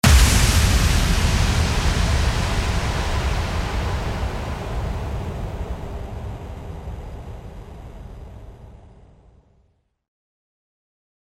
FX-1403-IMPACT
FX-1403-IMPACT.mp3